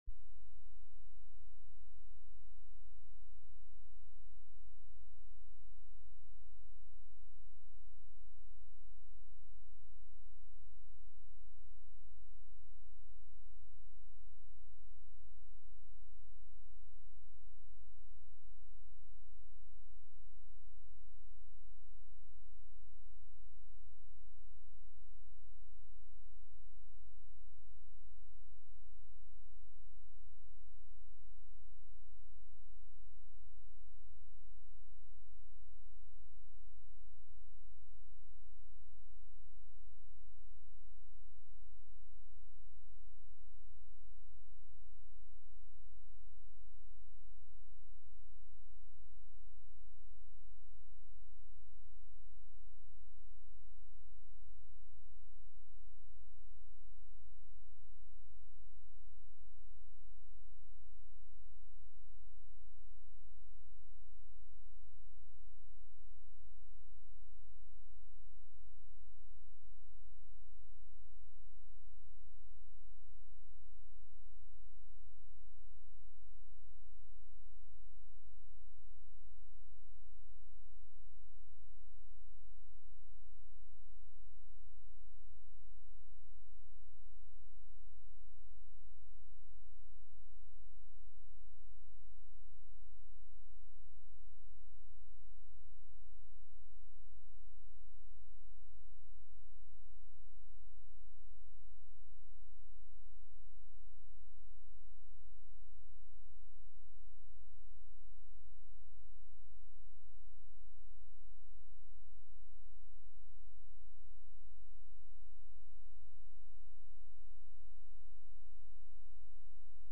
Service & Sunday School Christmas Program